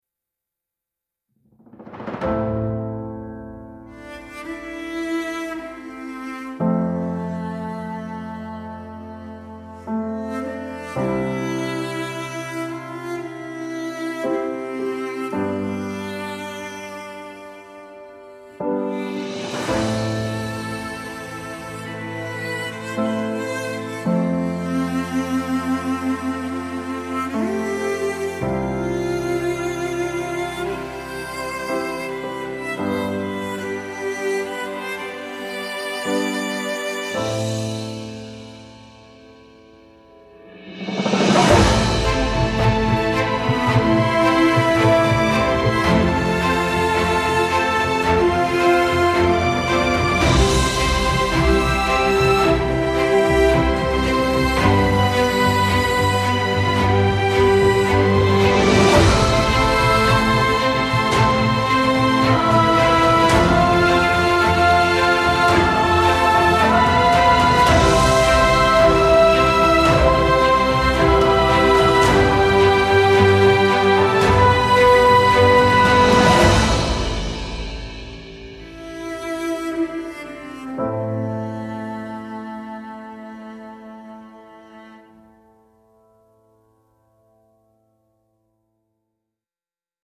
solemn respectful orchestral tribute with slow strings and french horn